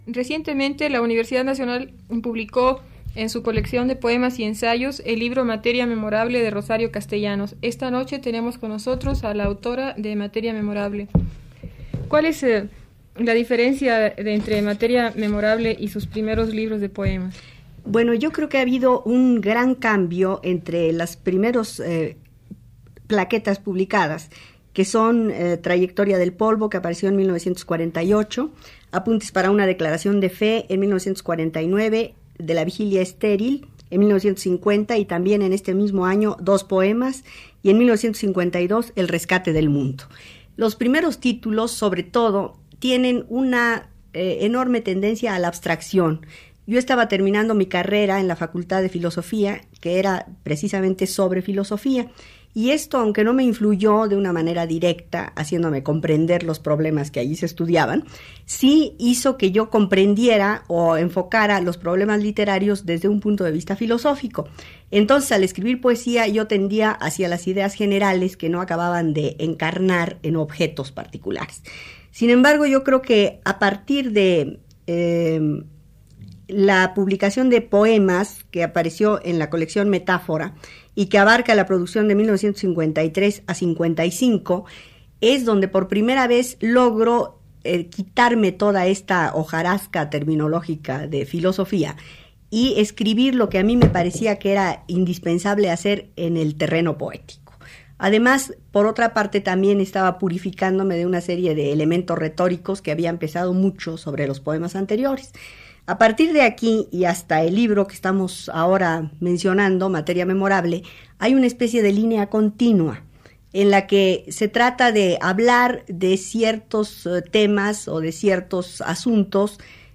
Entrevista a Rosario Castellanos
El 3 de noviembre de 1969 la escritora Rosario Castellanos concede una entrevista para Radio UNAM. Los temas mencionados durante la entrevista conciernen a la publicación de su libro de poesía Materia Memorable así como sus motivos de no escribir teatro y crítica literaria.
archivosonoro-entrevista-rosario-castellanos.mp3